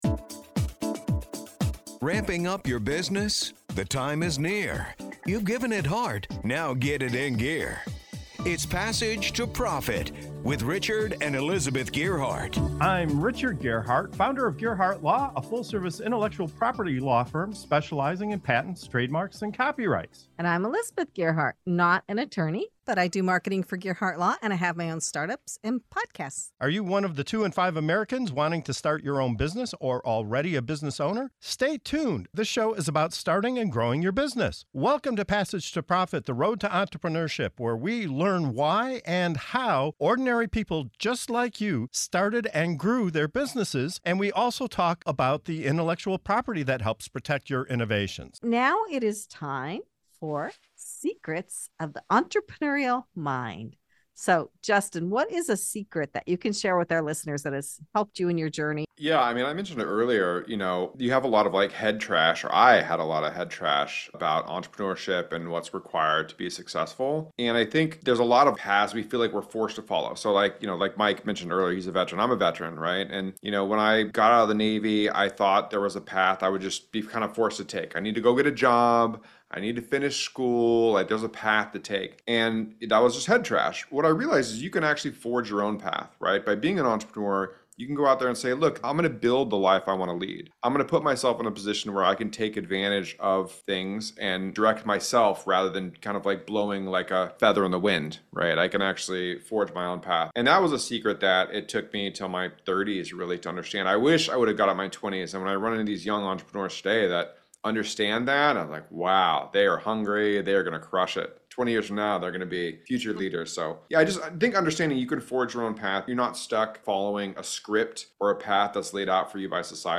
Dive into this segment of “Secrets of the Entrepreneurial Mind”, where veterans-turned-entrepreneurs reveal how they broke free from societal expectations and forged their own paths. Learn why picking the right partners is crucial, how celebrating small wins can keep you motivated, and the importance of understanding your unique journey.